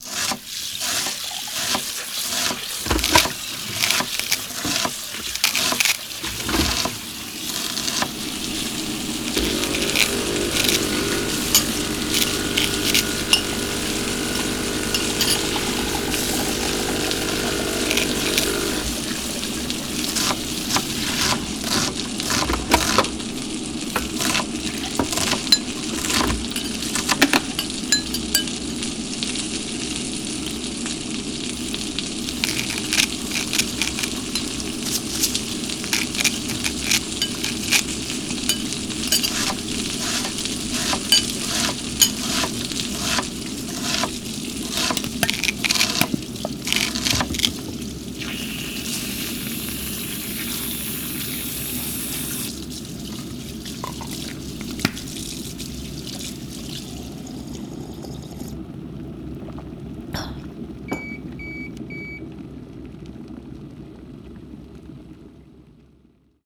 It is crucial that this is turned off in order to make the recordings the best quality they can be.
Kitchen Soundscape!